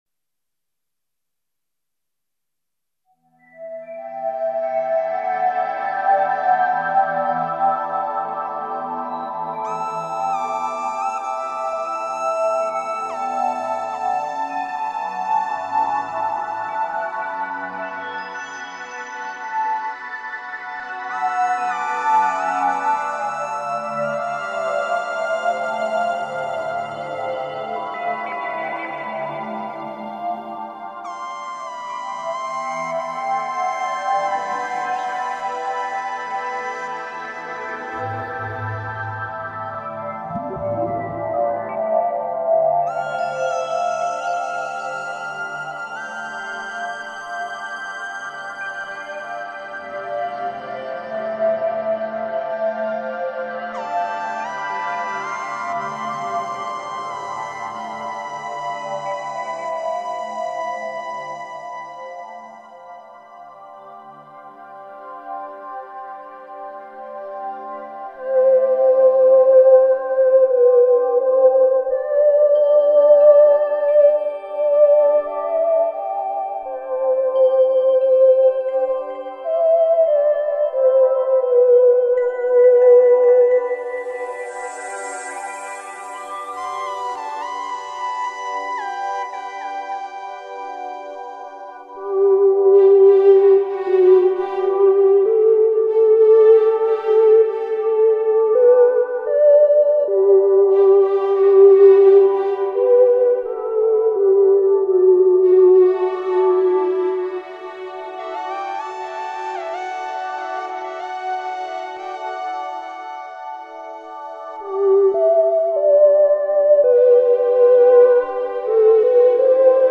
SOUNDTRACK MUSIC ; ILLUSTRATIVE MUSIC